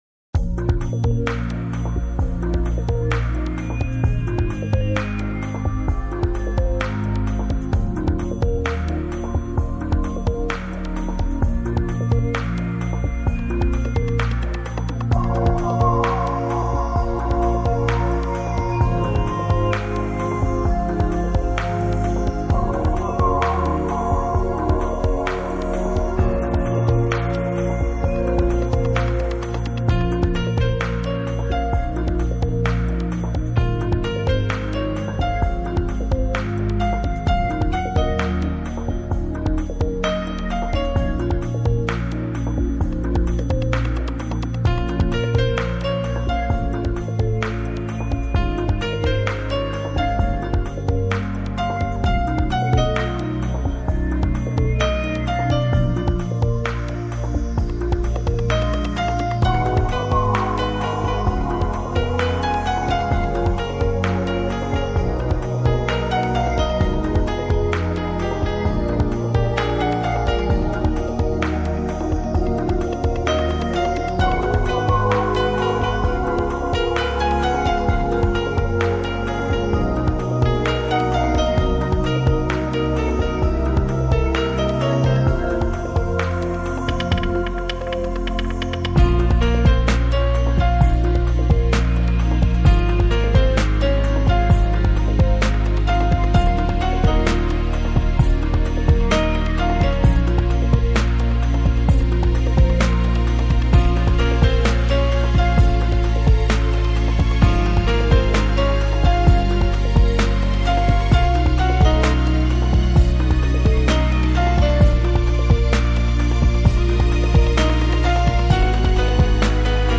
Красивая мелодия без слов
Krasivaya-melodiya-bez-slov.mp3